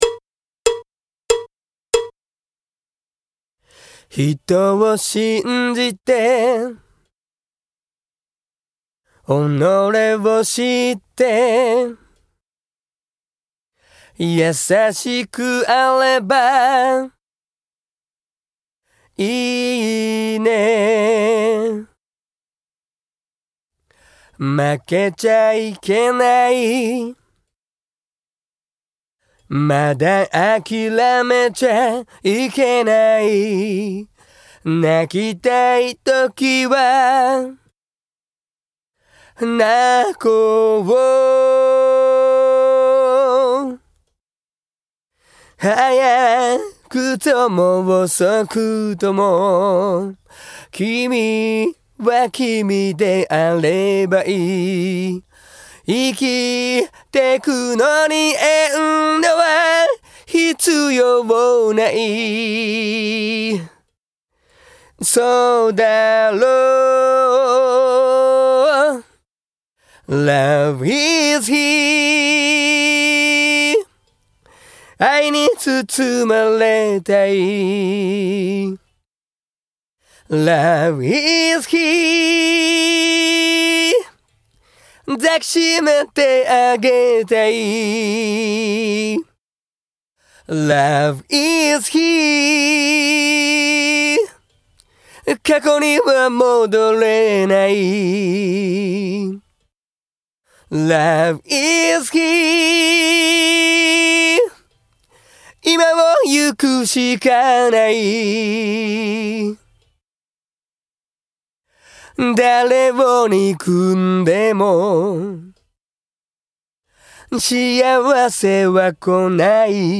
・ボーカルのみ　ＷＡＶ音源 /